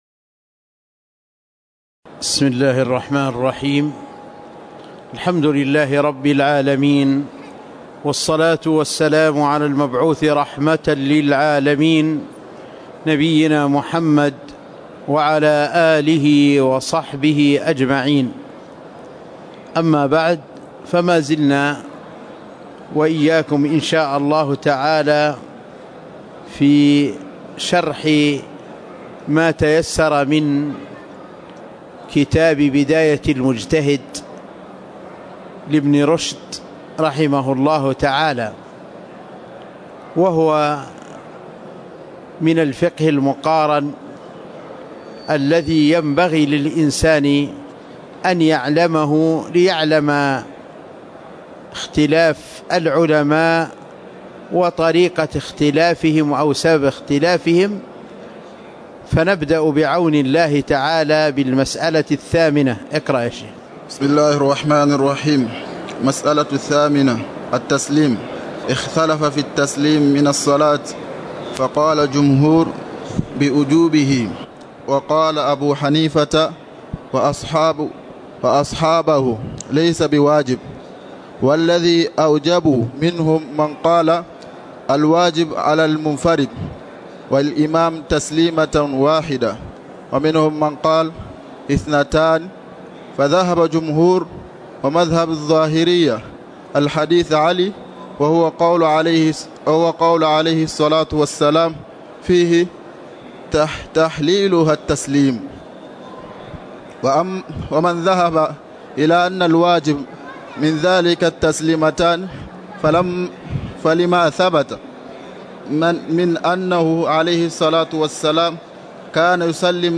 تاريخ النشر ٨ جمادى الآخرة ١٤٤١ هـ المكان: المسجد النبوي الشيخ